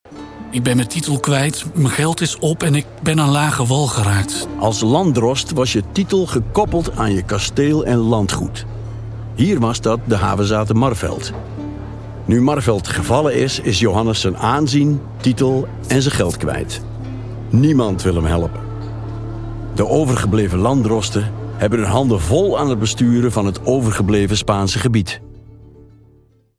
Fiable, Amicale, Corporative